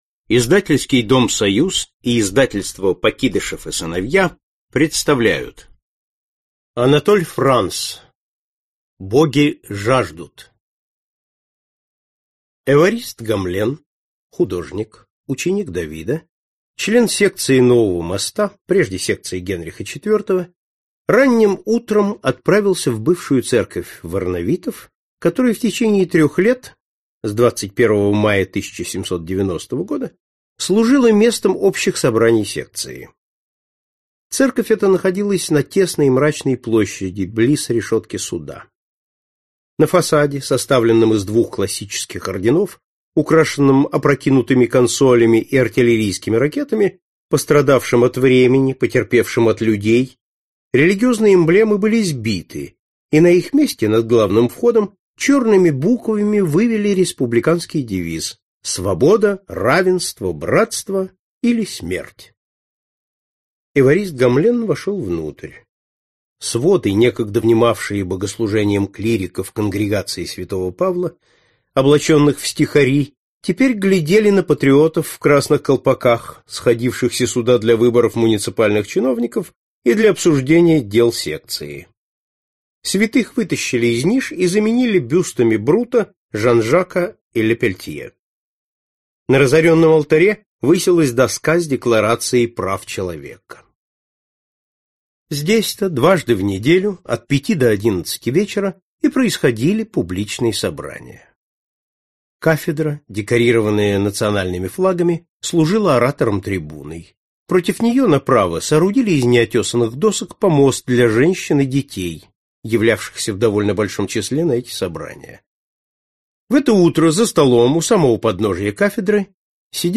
Аудиокнига Боги жаждут | Библиотека аудиокниг